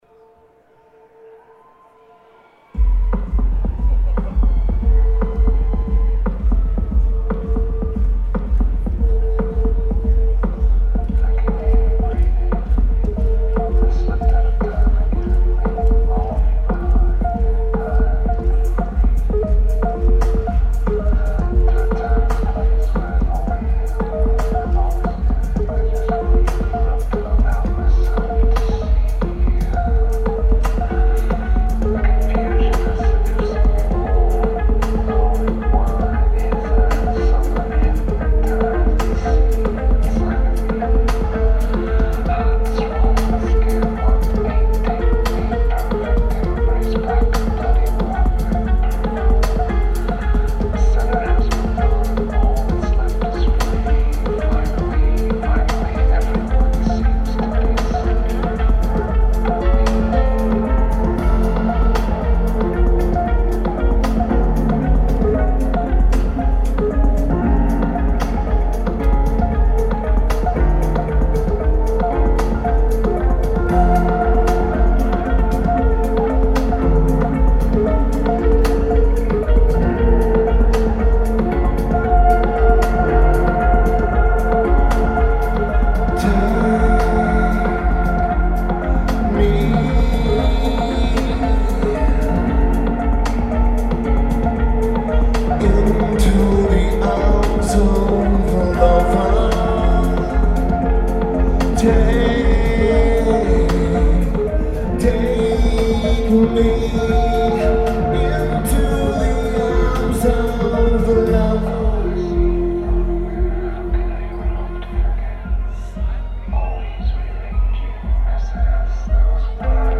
Bill Graham Civic Auditorium
Drums
Vocals/Guitar/Keyboards
Really good recordings and the setlists are well balanced.
Not to mention the bass is well balanced.